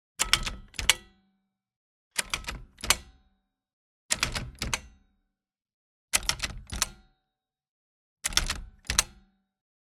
Движение рычага или ползунка 1